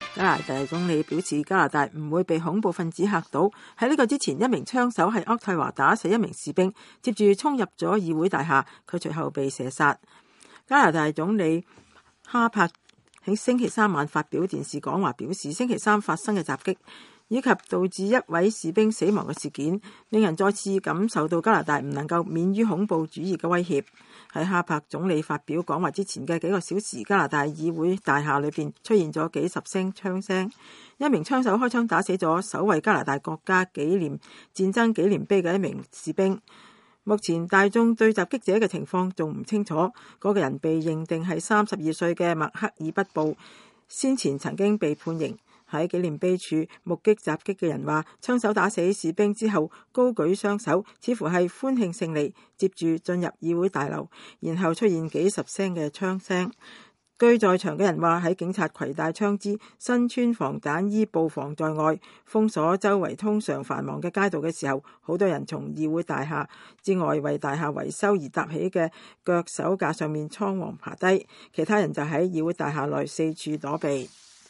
加拿大議會被槍擊 總理就事件講話